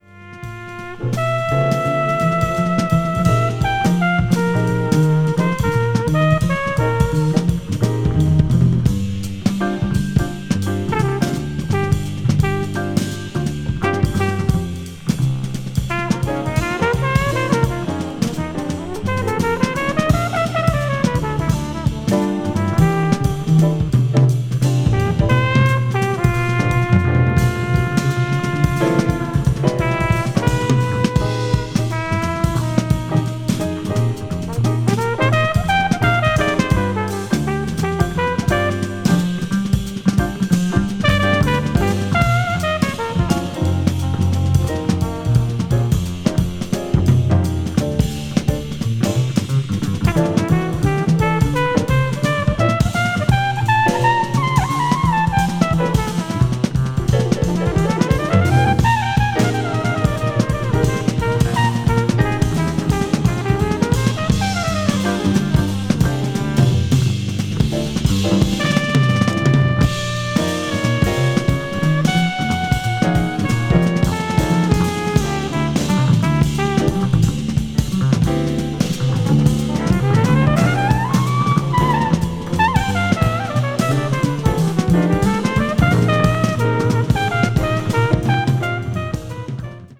contemporary jazz   modal jazz   post bop   spiritual jazz